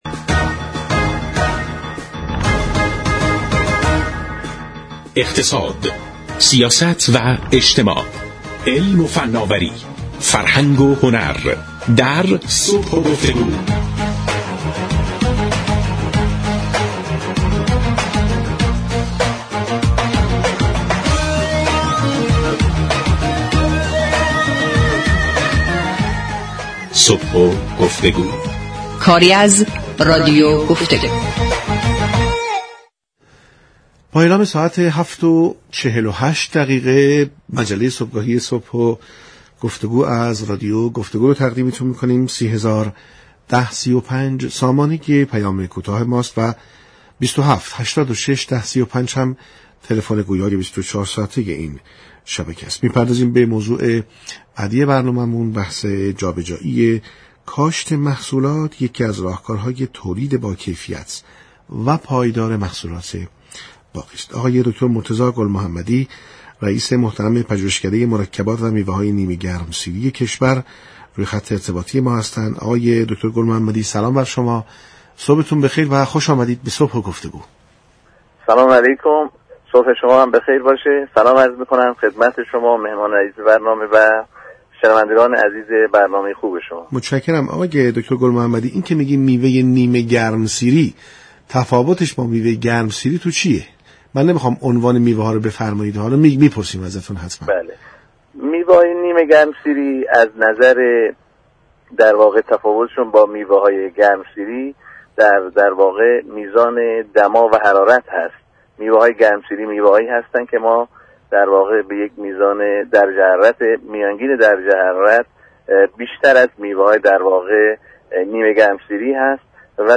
مصاحبه های علمی، فنی و ترویجی